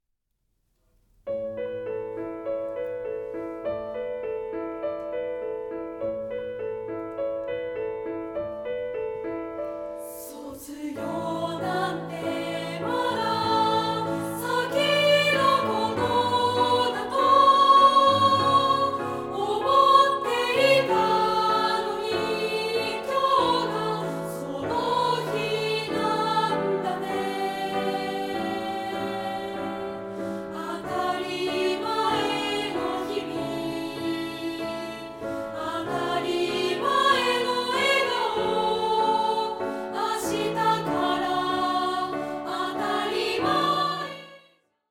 同声2部合唱／伴奏：ピアノ